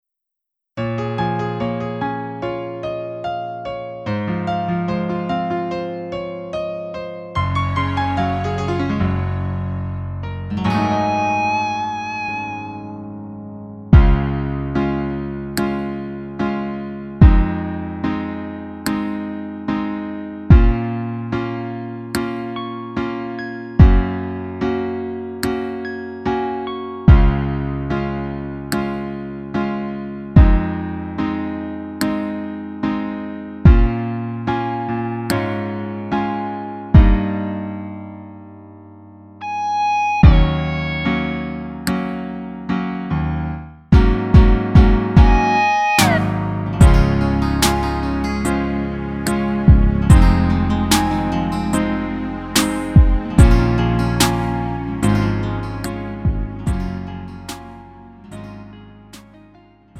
음정 -1키 3:57
장르 가요 구분